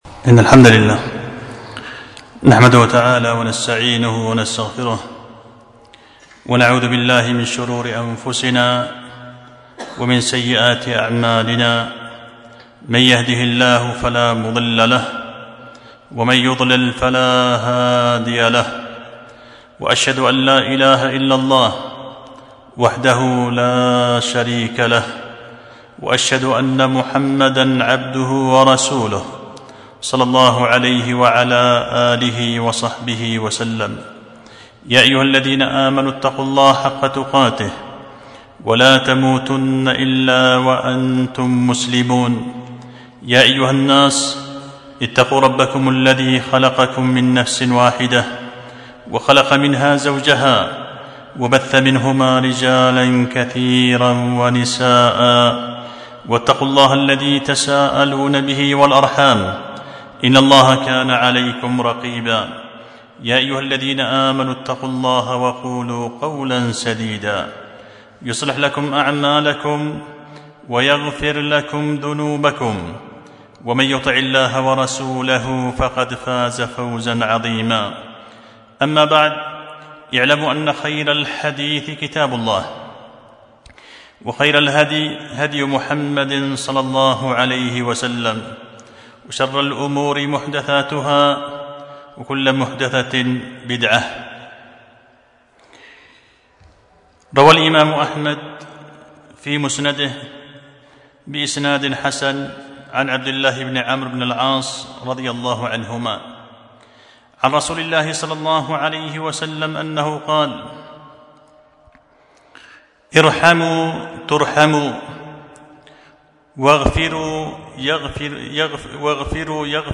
خطبة جمعة بعنوان فتح ذي الطَّول لمعنى حديث (ويل لأقماع القول ٠)